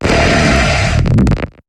Cri de Darkrai dans Pokémon HOME.